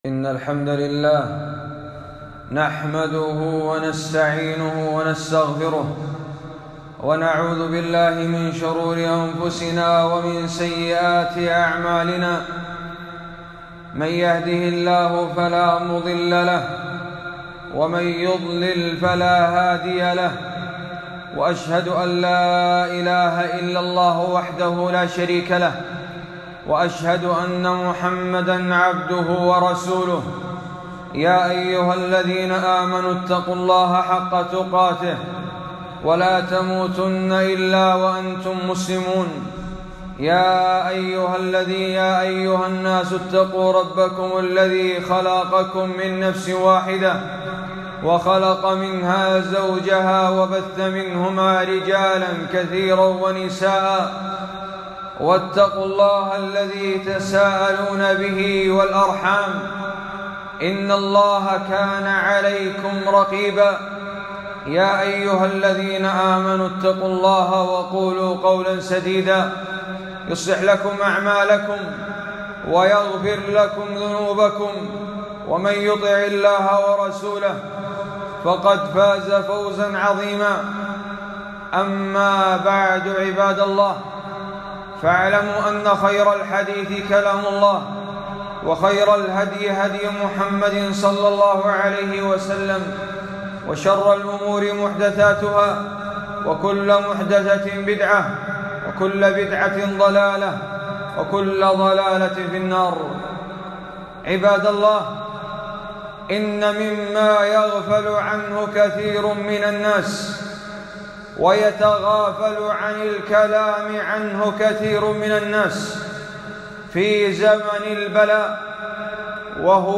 خطبة - الإجراءات الشرعية التي يرتفع بها البلاء